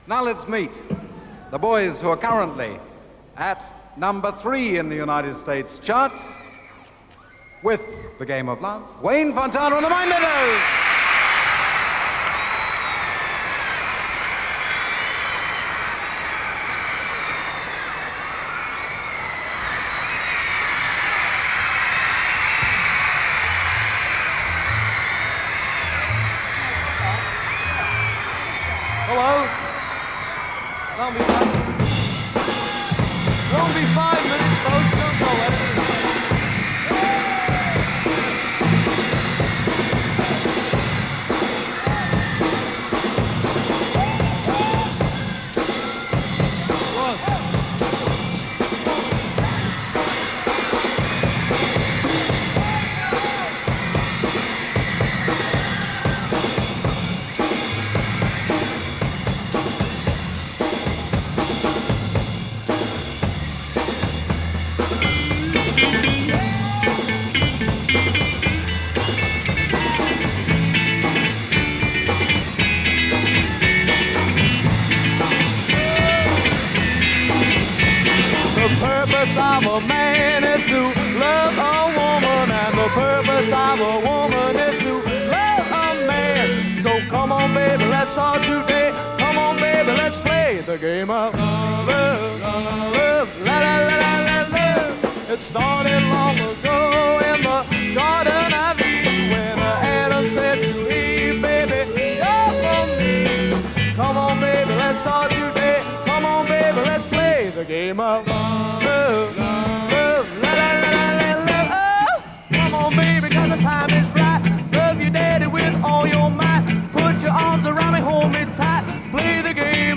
live at the 1965 NME Poll Winners and recorded on April 11